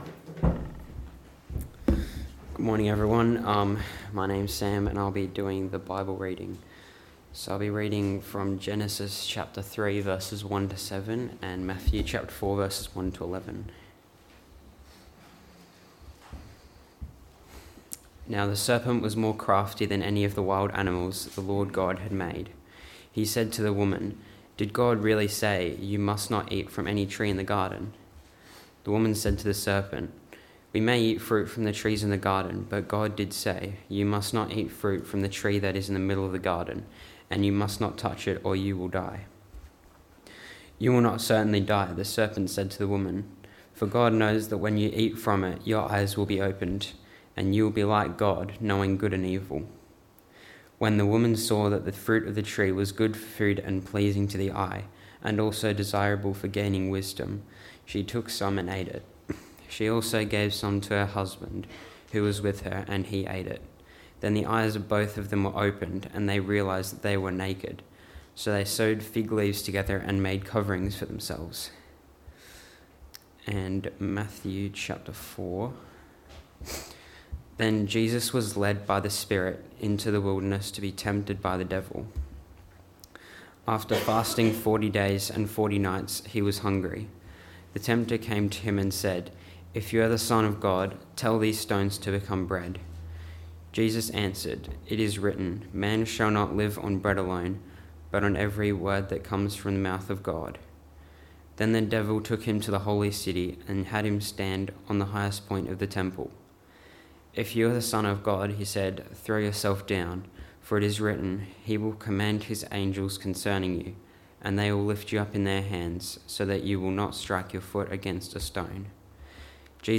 Text: Genesis 3: 1-7 Sermon